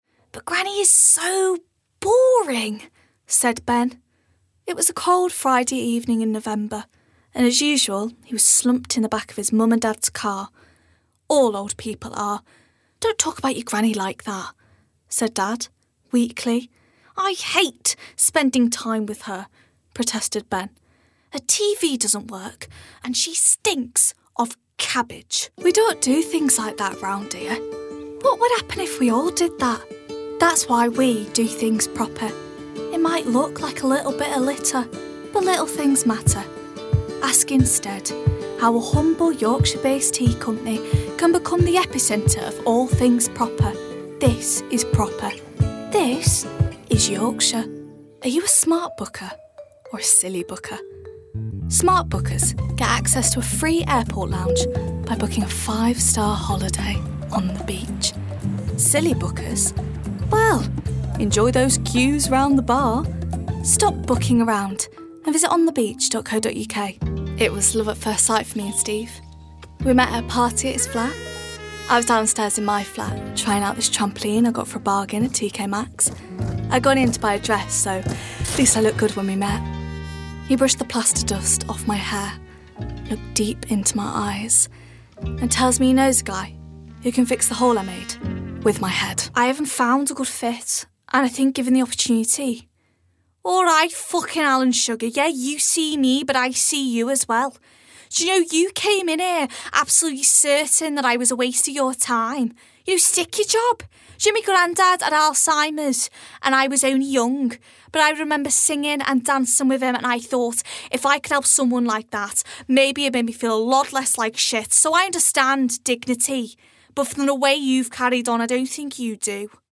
Yorkshire
Voicereel:
BRITISH ISLES: Heightened RP, Contemporary RP, Liverpool, Northern-Irish, MLE
GLOBAL: Standard-American, American Southern States